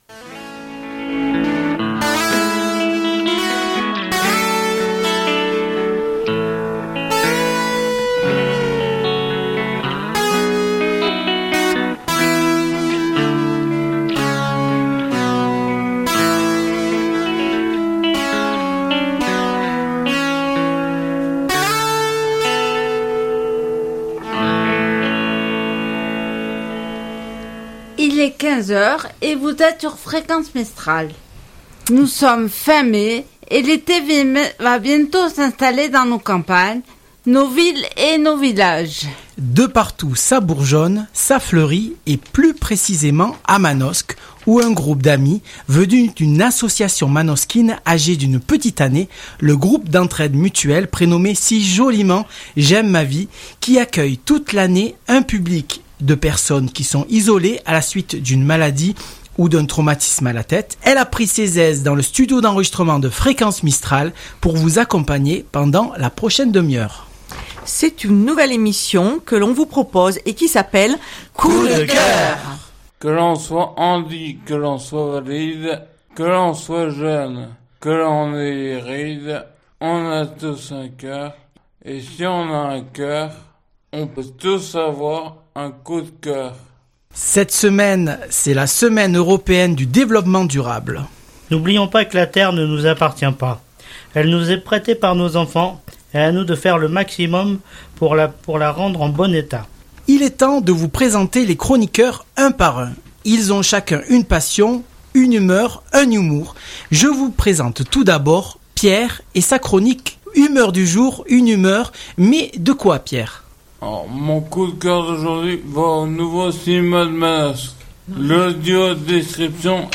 C'est d'ailleurs le titre de cette émission réalisée dans notre studio que nous vous invitons à réécouter...